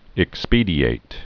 (ĭk-spēdē-āt)